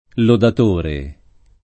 [ lodat 1 re ]